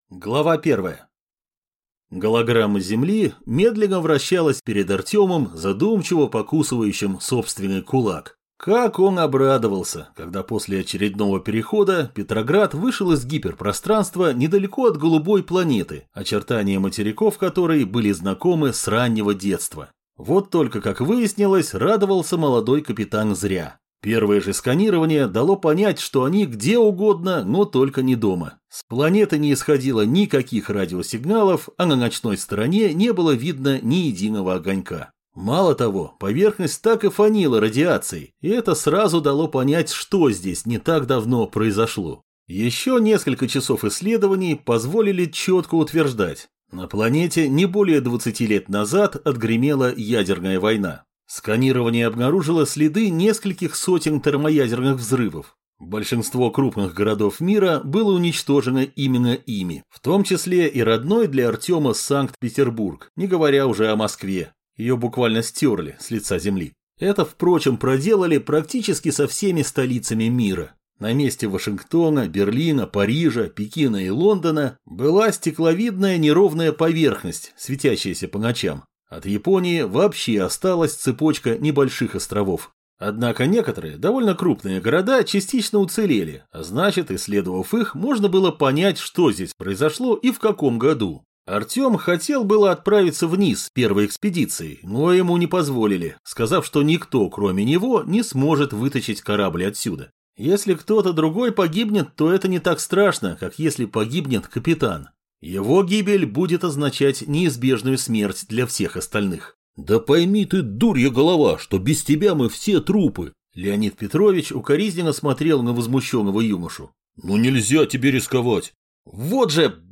Аудиокнига Хроники дальних дорог | Библиотека аудиокниг
Прослушать и бесплатно скачать фрагмент аудиокниги